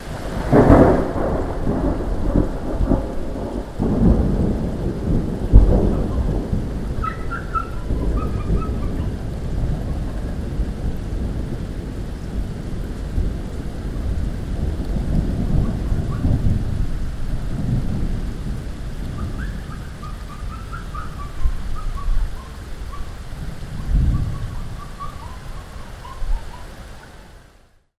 This is my thunderclap